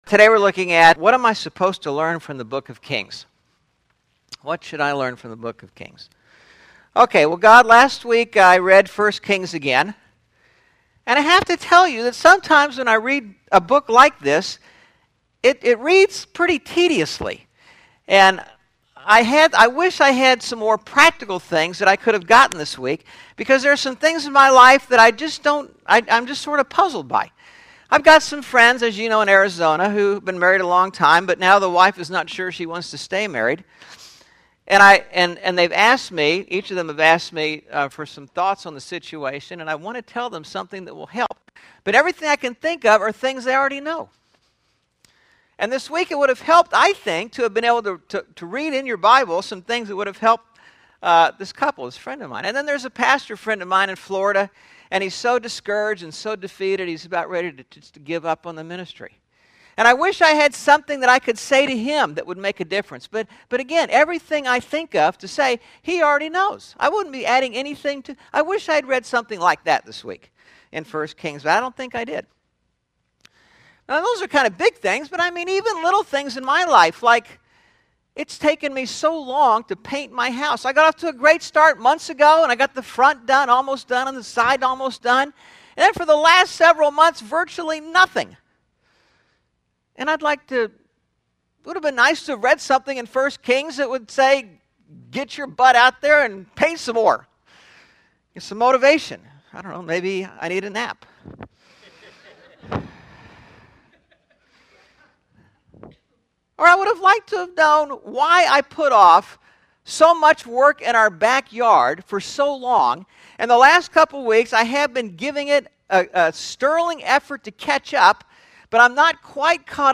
8/28/11 Sermon (The Book of 1 Kings) – Churches in Irvine, CA – Pacific Church of Irvine